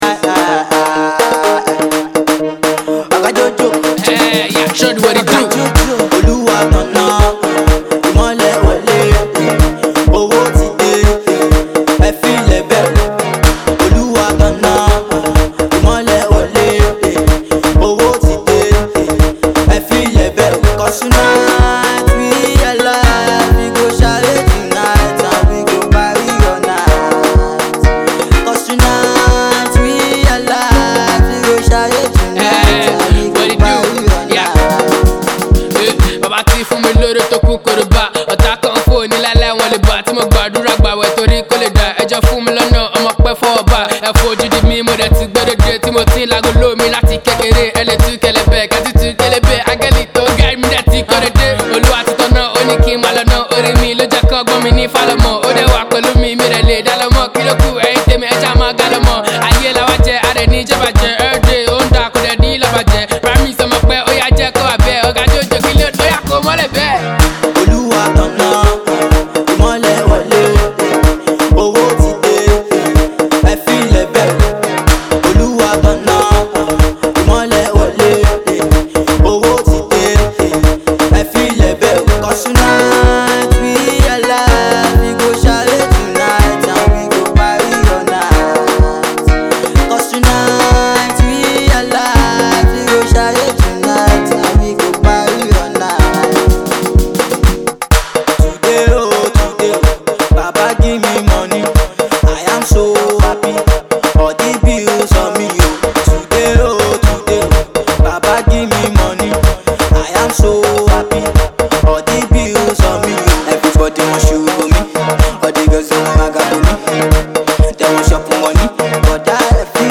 Afro-pop
hard-bopping number